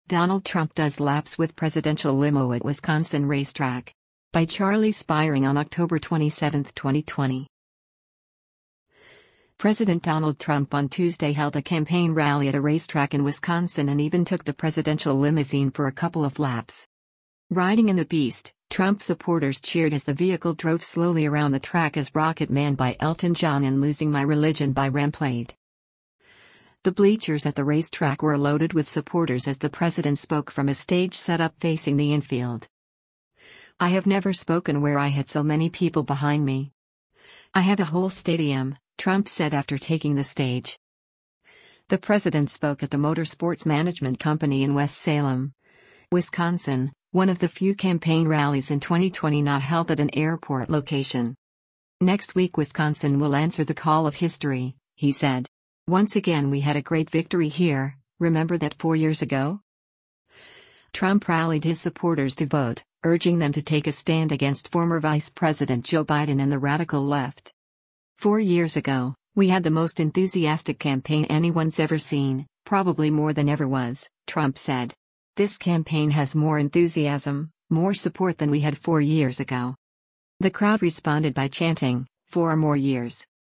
President Donald Trump on Tuesday held a campaign rally at a racetrack in Wisconsin and even took the presidential limousine for a couple of laps.
Riding in “The Beast,” Trump supporters cheered as the vehicle drove slowly around the track as “Rocket Man” by Elton John and “Losing my Religion” by REM played.
The crowd responded by chanting, “Four more years!”